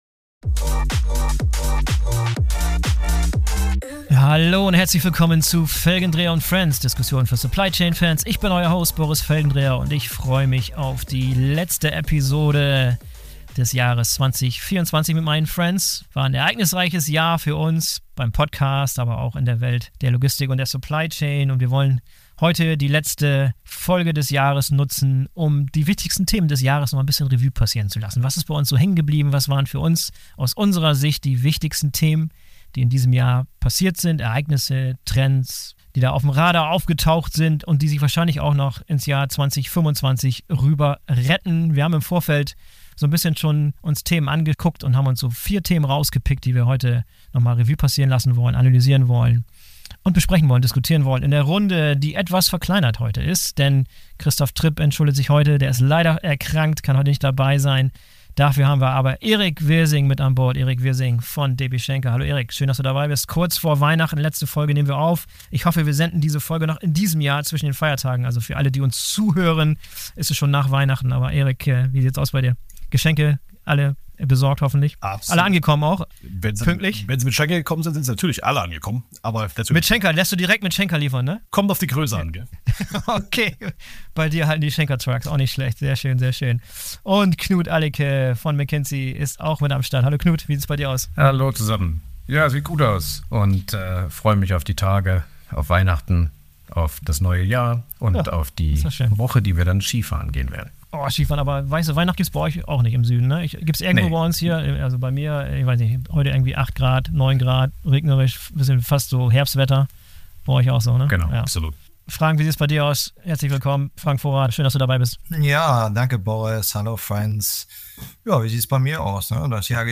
Wir diskutieren Supply Chain und Logistik Trends und Themen, die im Jahr 2024 besonders relevant geworden sind, die uns aber im kommenden Jahr 2025 noch alle erheblich beschäftigen werden.